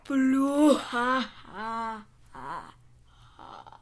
邪恶的笑声包 " 邪恶的笑声4
描述：一个非常安静的、愚蠢的邪笑。
Tag: 邪恶的笑 哈哈 女孩 愚蠢的 邪恶的 安静的 蓝色的 蓝色